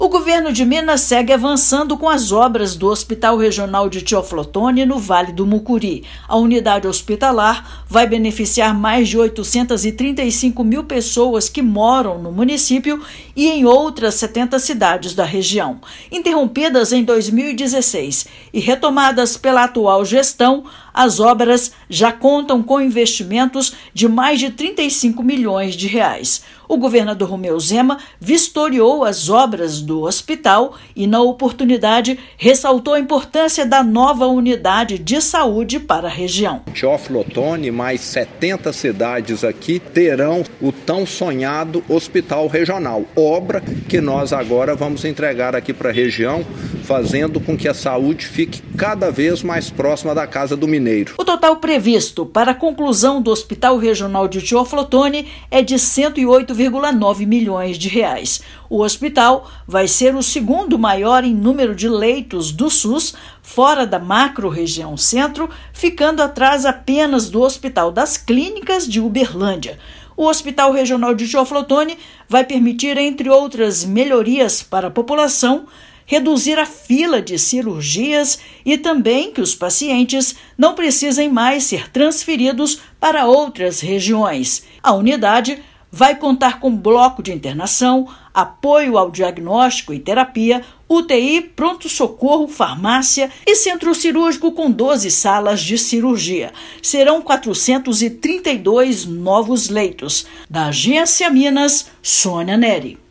[RÁDIO] Governo de Minas vistoria avanços nas obras do Hospital Regional de Teófilo Otoni
Unidade de saúde, que teve intervenções retomadas na atual gestão, vai beneficiar mais de 800 mil pessoas no Vale do Mucuri. Ouça matéria de rádio.